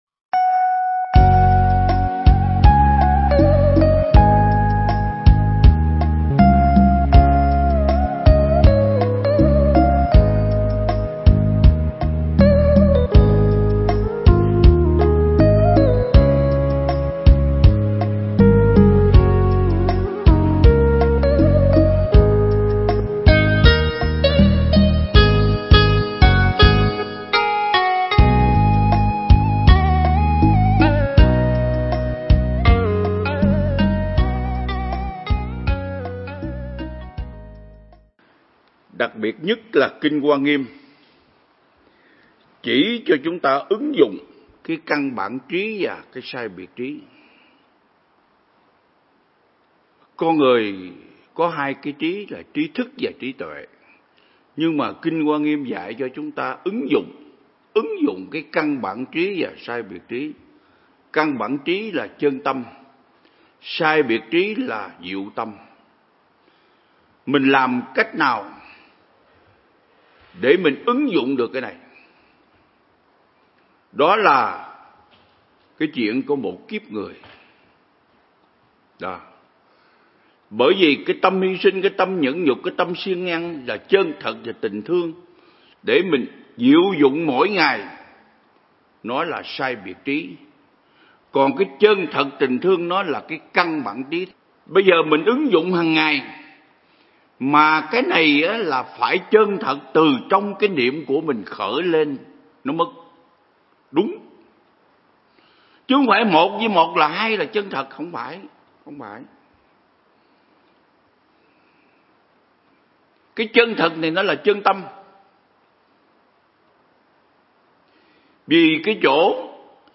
Mp3 Pháp Thoại Ứng Dụng Triết Lý Hoa Nghiêm Phần 22
giảng tại Viện Nghiên Cứu Và Ứng Dụng Buddha Yoga Việt Nam (TP Đà Lạt)